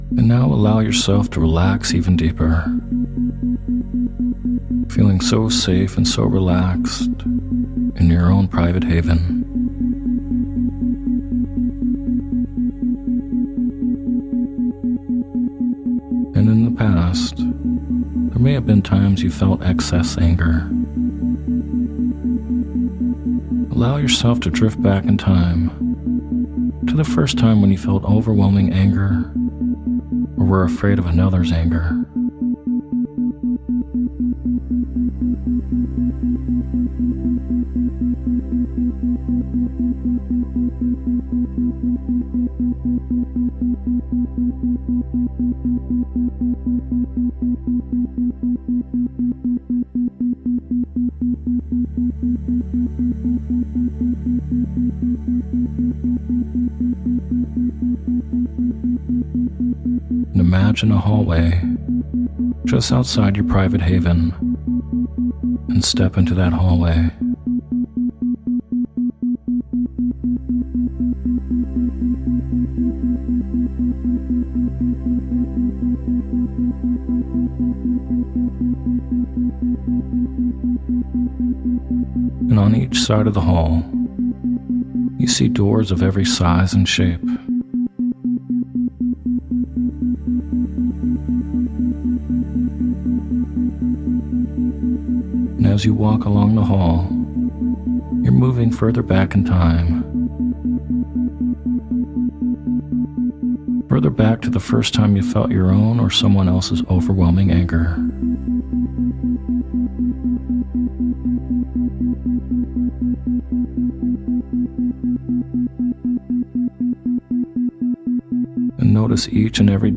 Sleep Hypnosis For Releasing Anger With Isochronic Tones